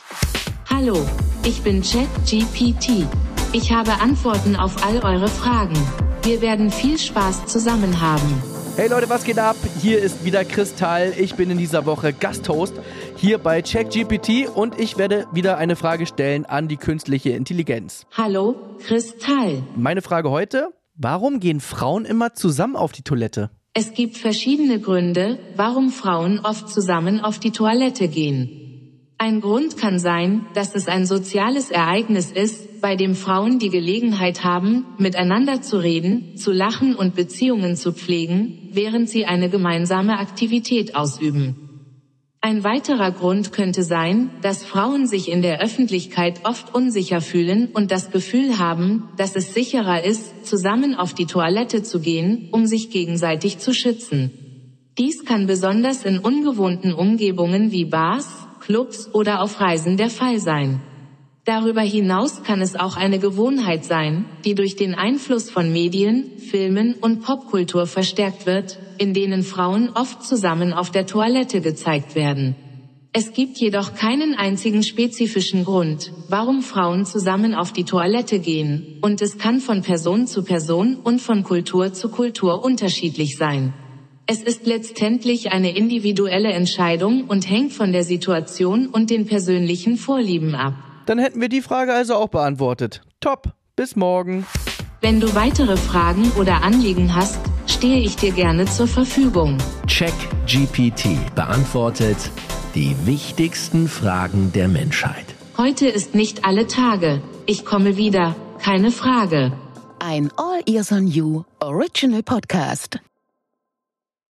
Chris Tall & KI
Künstliche Intelligenz beantwortet die wichtigsten Fragen der Menschheit